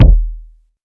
Kicks
KICK.18.NEPT.wav